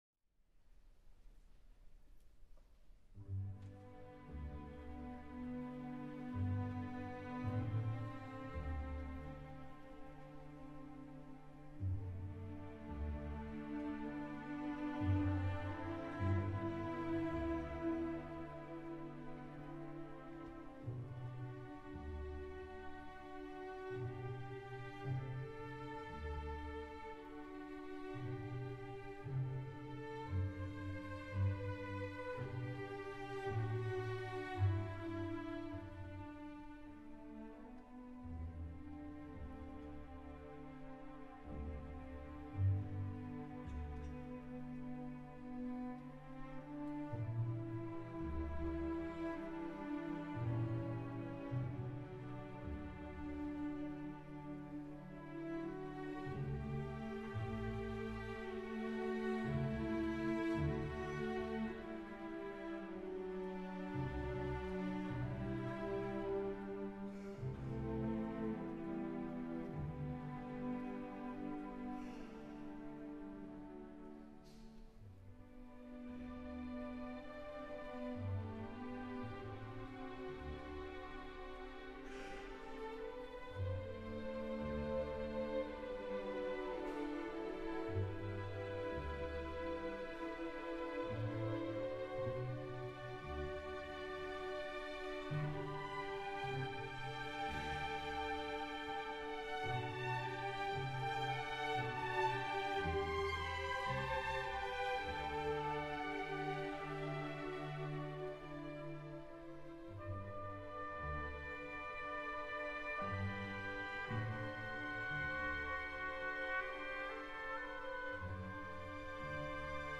A mixture of happy, sad, scared, adventurous and any number of other emotions as we are taken on the musical journey of a magnificent piece of music!